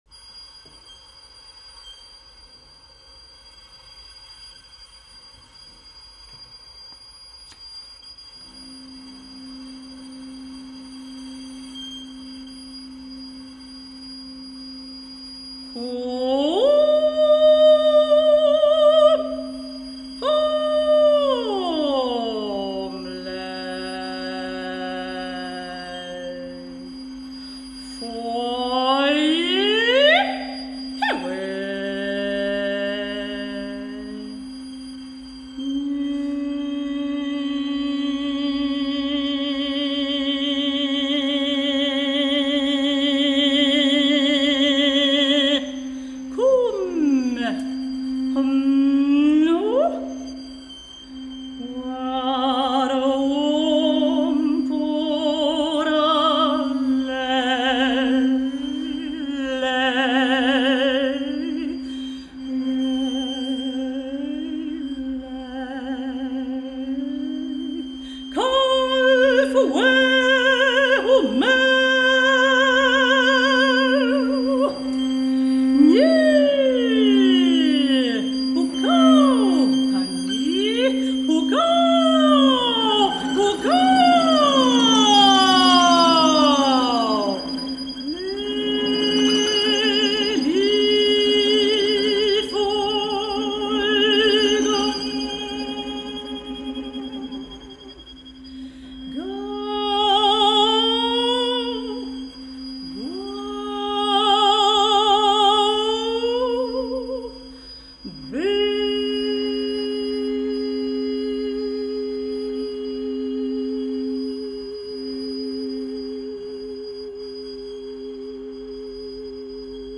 Epigramas Mapuches para contralto, clarinete, violín, cello y piano
Música vocal